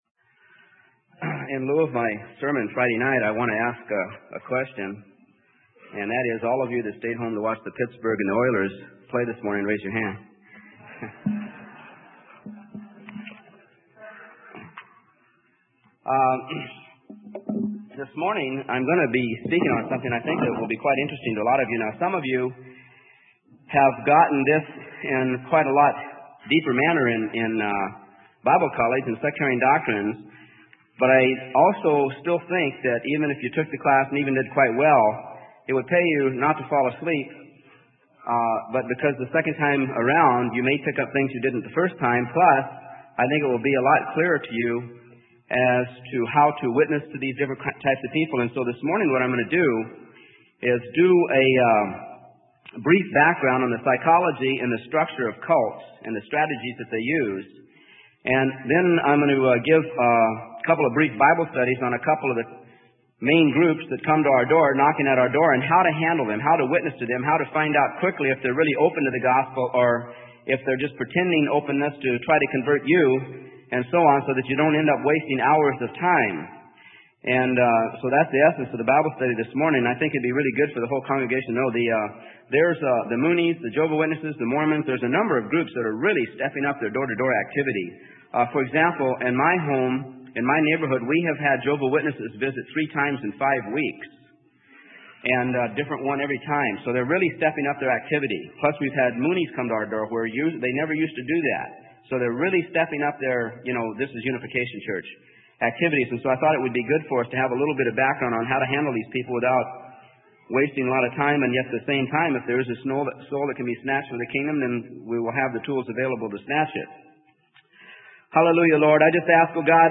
Sermon: The Psychology & Culture of Cults - Freely Given Online Library